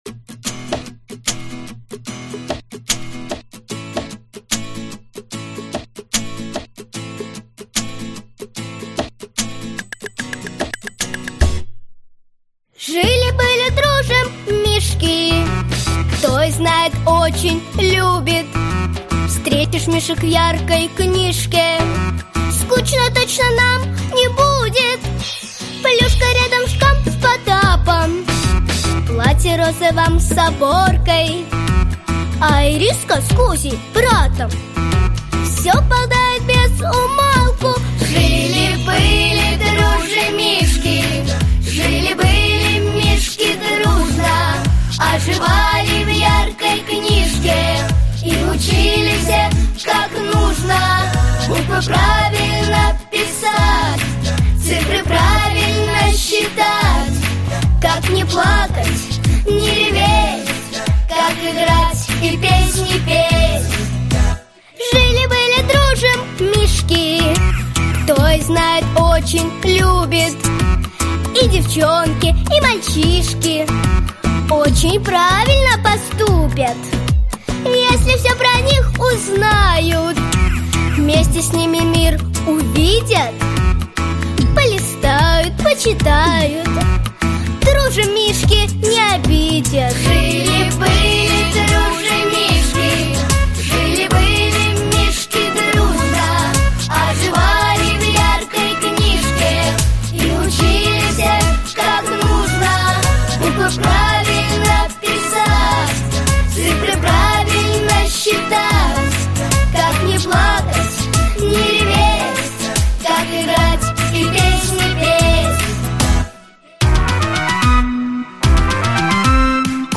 Аудиокнига Моя первая развивающая энциклопедия | Библиотека аудиокниг